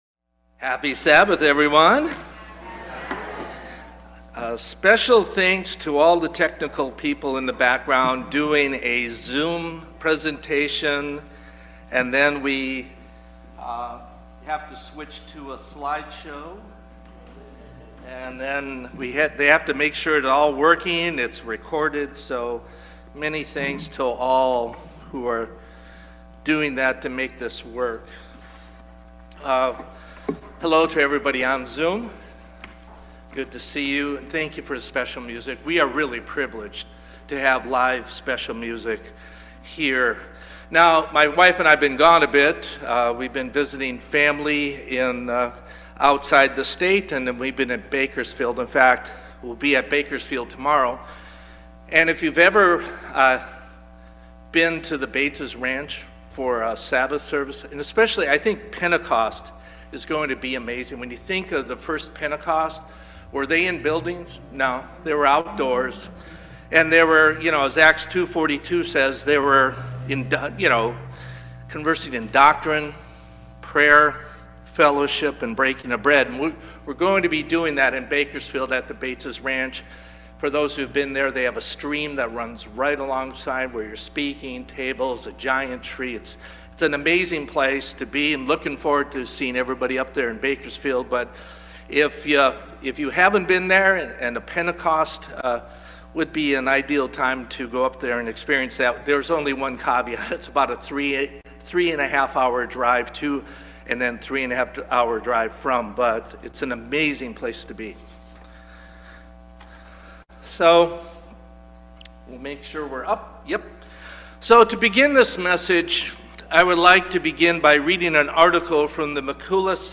Sermons
Given in Bakersfield, CA Los Angeles, CA